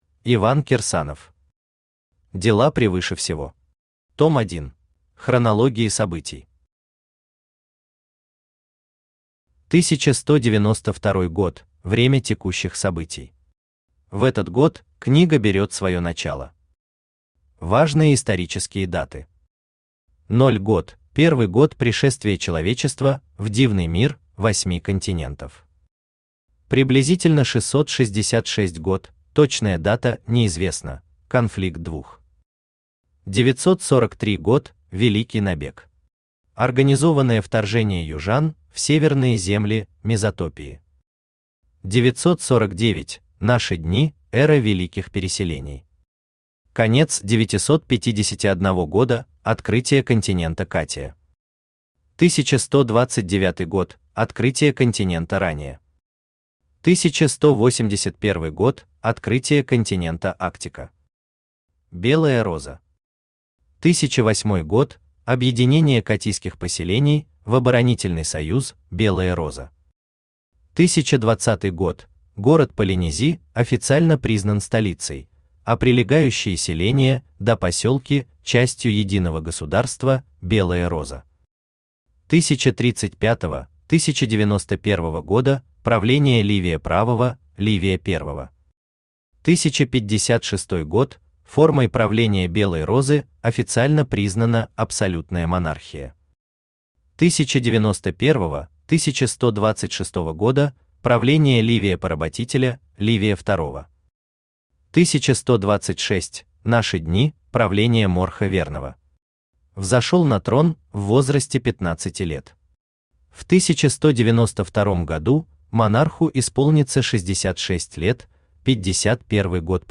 Аудиокнига Дела превыше всего. Том 1 | Библиотека аудиокниг
Том 1 Автор Иван Сергеевич Кирсанов Читает аудиокнигу Авточтец ЛитРес.